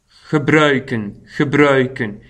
PRONONCIATION